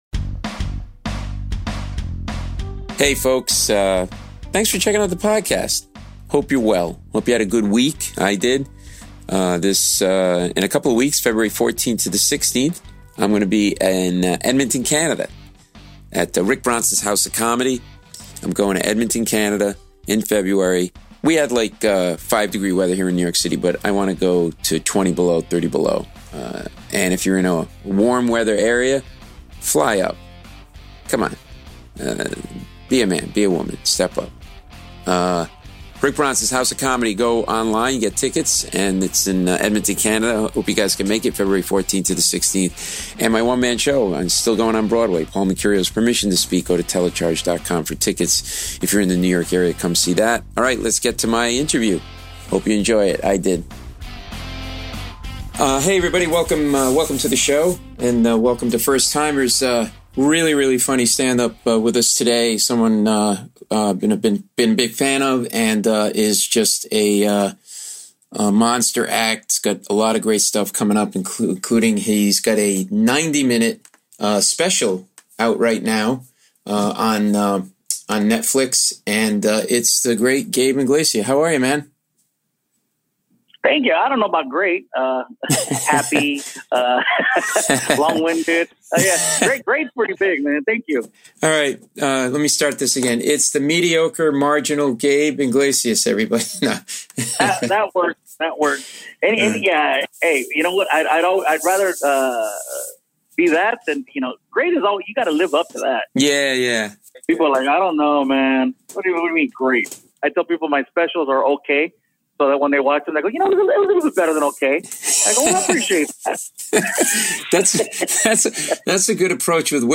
Gabe Iglesias aka "Fluffy" - Comedian (Paul Mecurio interviews Gabriel Iglesias; 04 Feb 2019) | Padverb